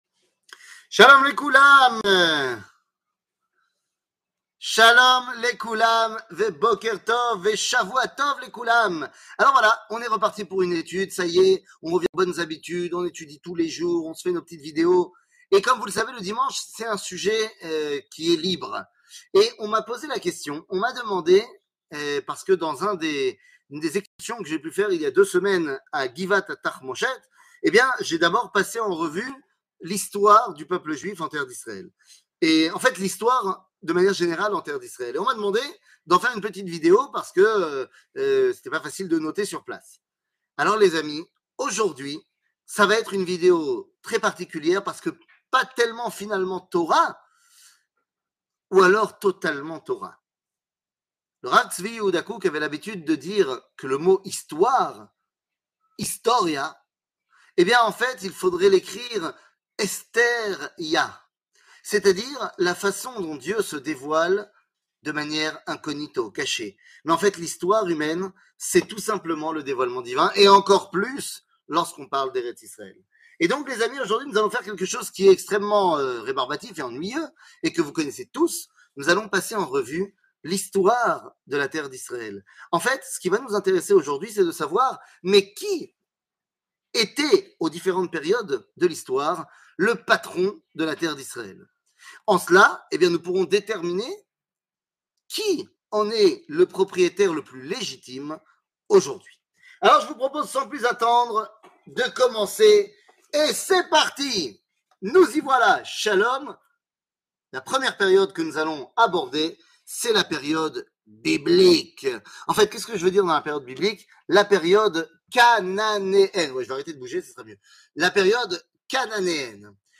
שיעור מ 12 יוני 2022 11MIN הורדה בקובץ אודיו MP3 (10.75 Mo) הורדה בקובץ וידאו MP4 (42.98 Mo) TAGS : שיעורים קצרים